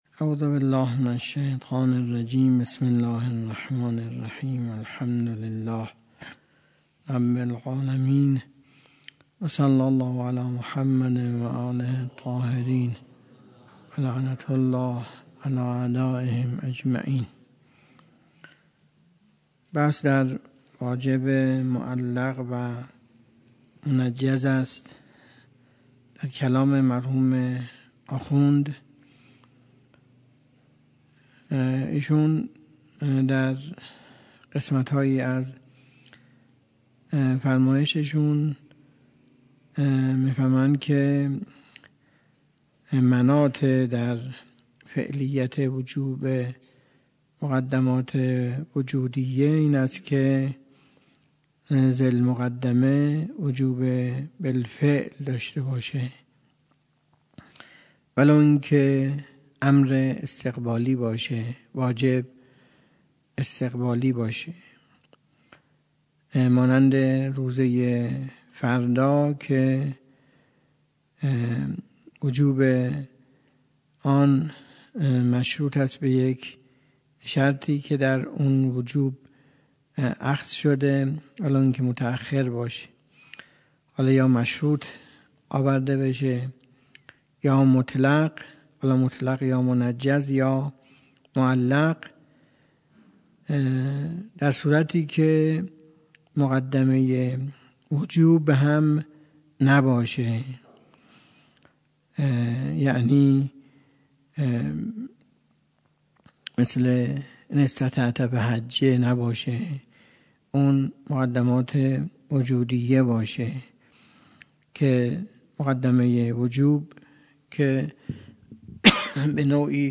درس خارج اصول
سخنرانی